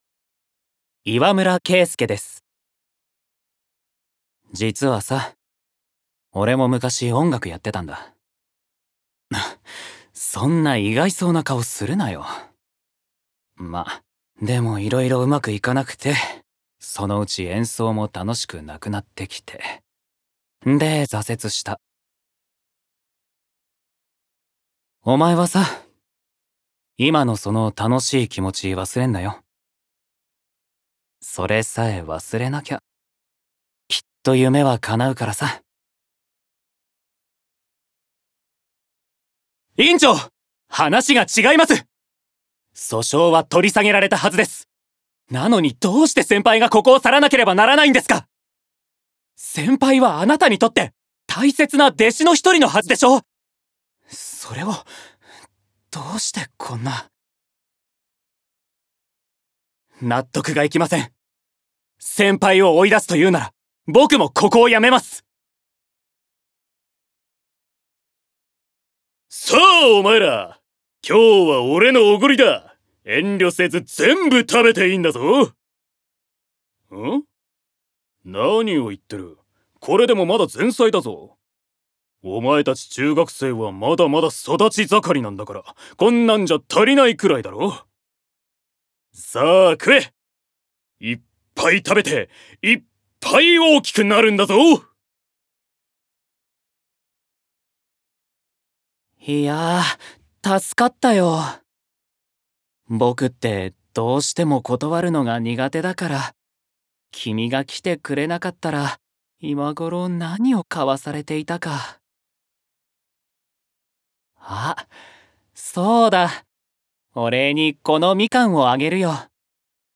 Voice Sample
ボイスサンプル